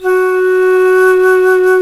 Index of /90_sSampleCDs/Roland L-CDX-03 Disk 1/FLT_C Flutes 3-8/FLT_C Flt Brt 3